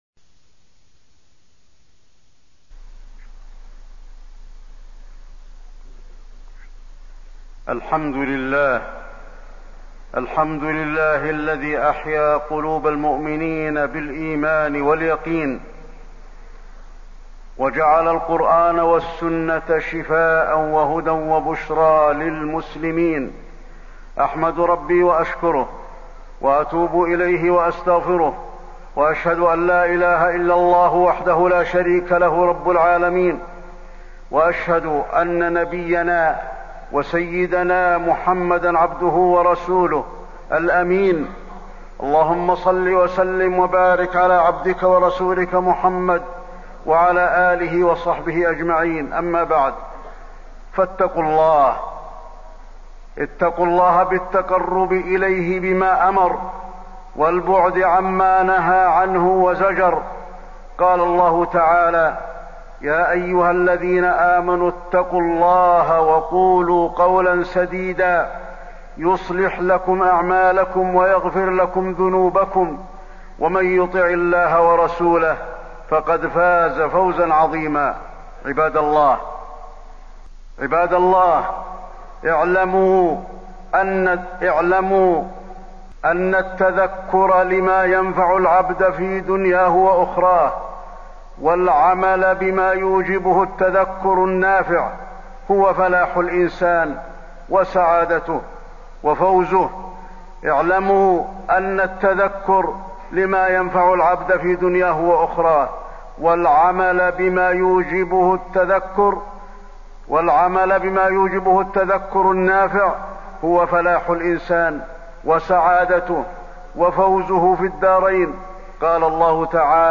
تاريخ النشر ٢٩ ربيع الأول ١٤٣٢ هـ المكان: المسجد النبوي الشيخ: فضيلة الشيخ د. علي بن عبدالرحمن الحذيفي فضيلة الشيخ د. علي بن عبدالرحمن الحذيفي التذكر حقائقه وتفصيله